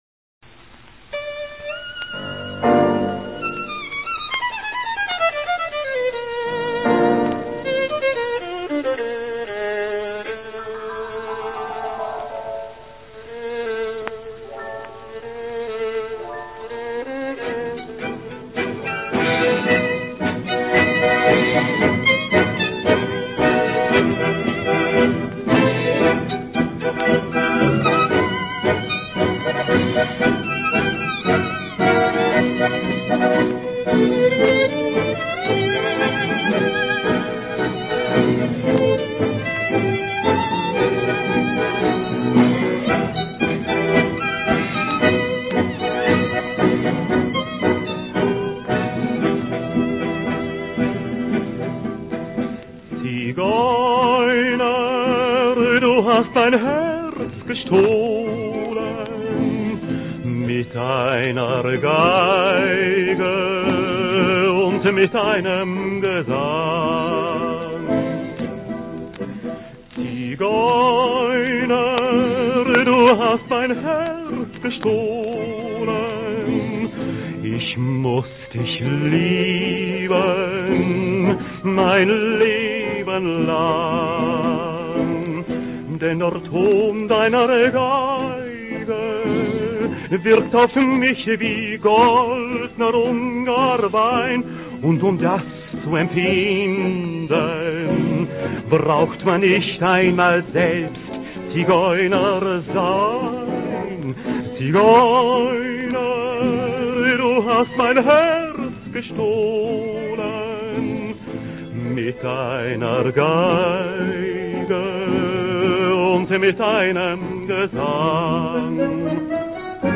танцевальный оркестр